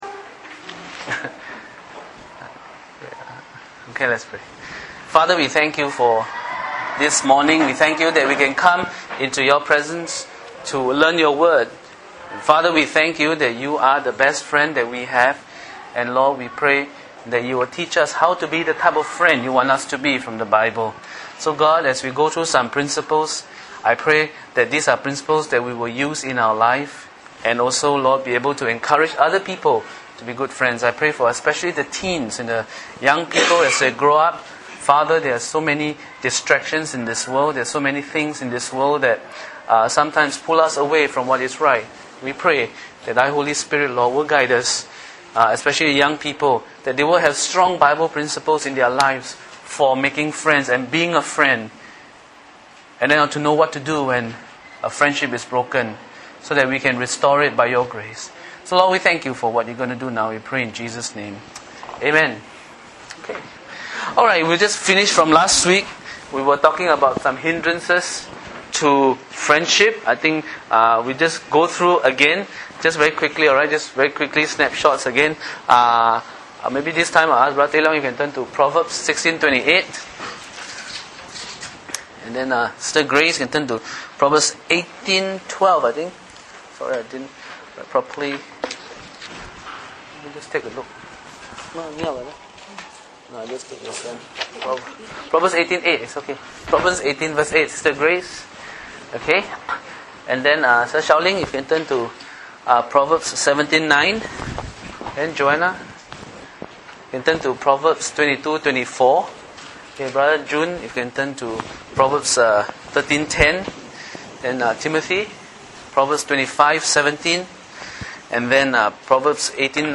Initiating and restoring broken relationships Preached by